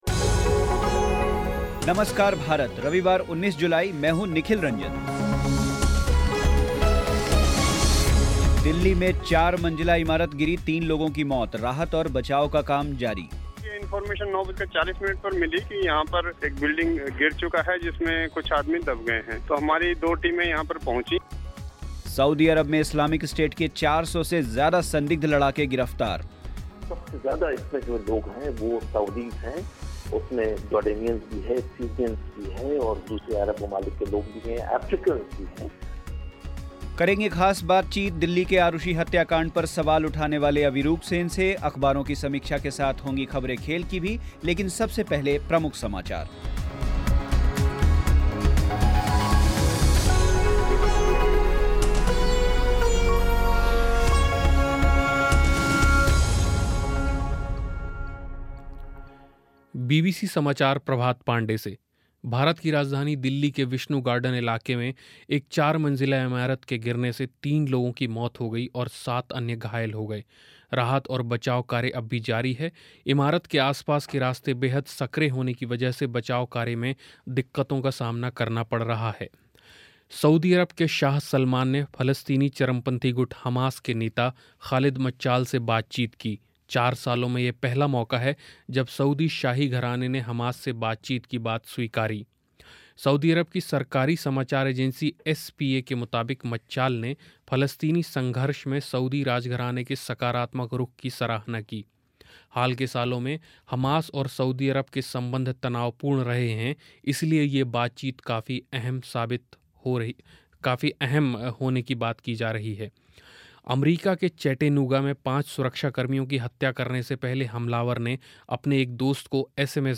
दिल्ली में गिरी चार मंजिला इमारत, तीन लोगों की मौत, राहत और बचाव का काम जारी सऊदी अरब में इस्लामिक स्टेट के 400 से ज्यादा संदिग्ध लड़ाके गिरफ़्तार ख़ास बातचीत